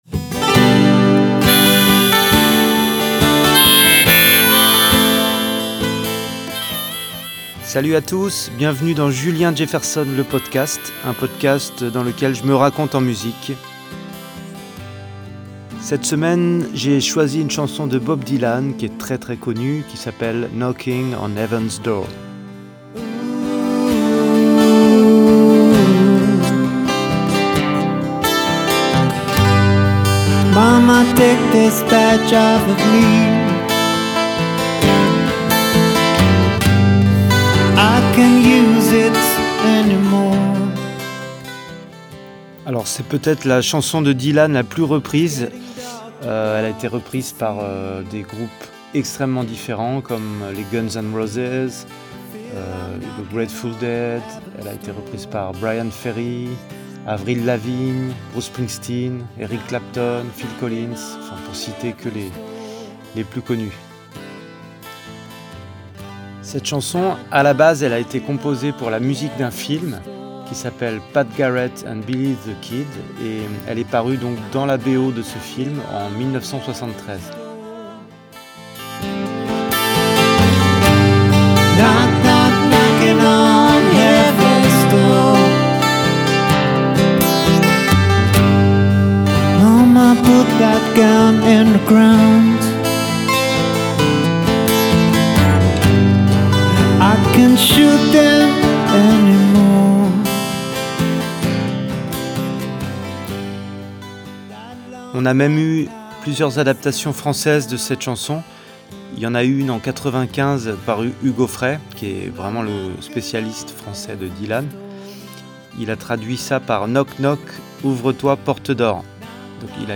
et nous en livre une version acoustique et intimiste.